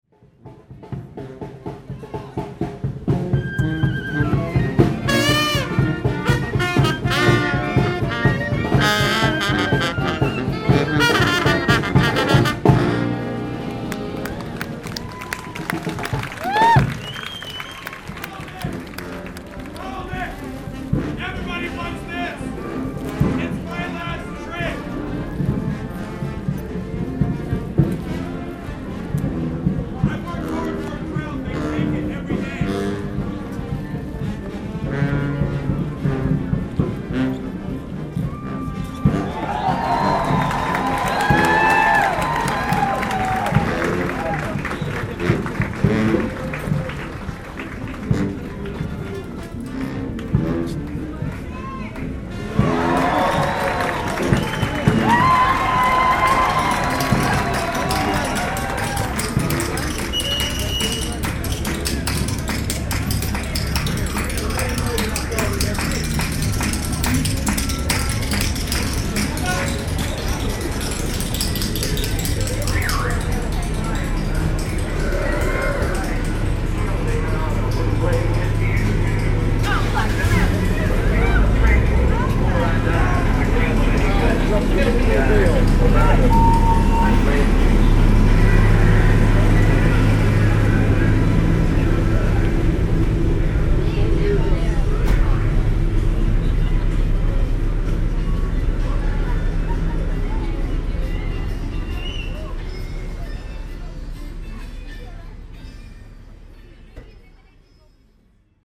For this exhibition I decided to use location recordings I made in New Orleans prior to Hurricane Katrina. Using segments from various spots around New Orleans of street entertainers, musicians, and incidental sounds, I created an hour long collage that plays back continuously. Since the recordings were made with a pair of binaural microphones, headphone listening is the ideal situation in which to recreate the sonic environment of the original locations.